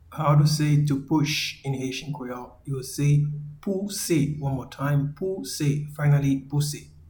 Pronunciation and Transcript:
to-Push-in-Haitian-Creole-Pouse.mp3